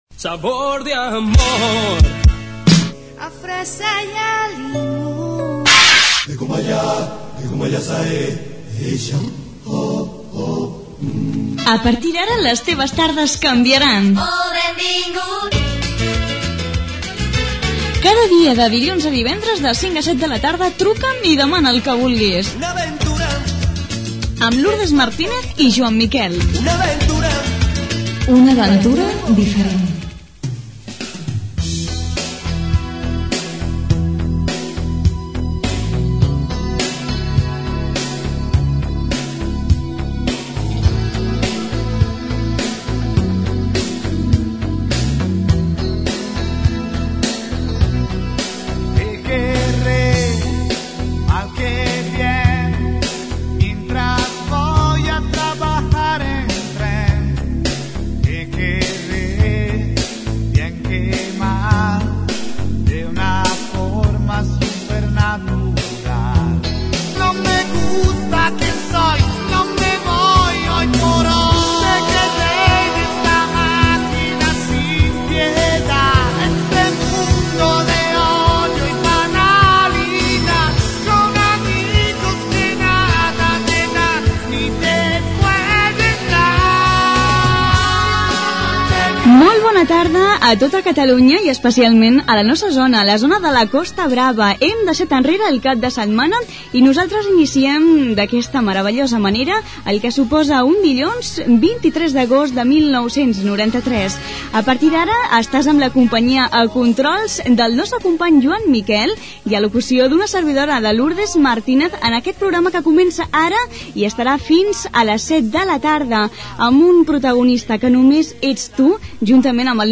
Careta del programa, salutació, tema musical dedicat i trucada telefònica.
FM